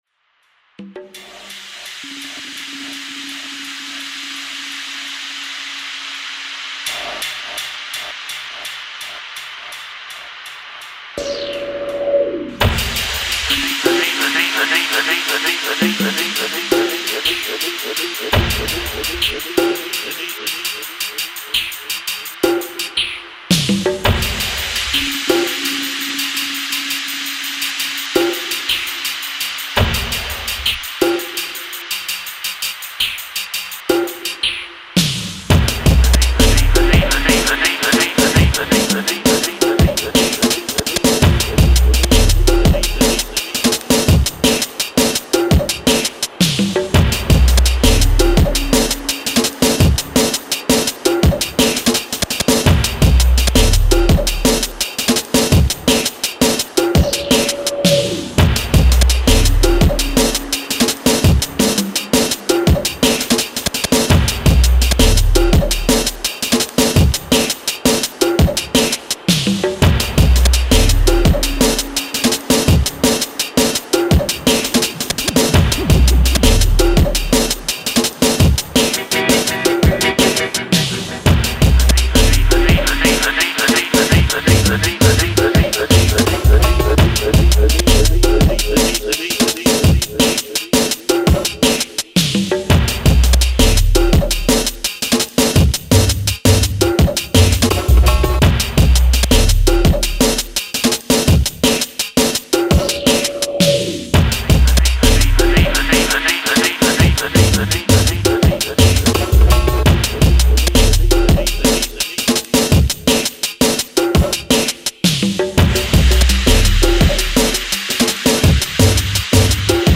futuristic dark sounds
the early Techstep scene in DNB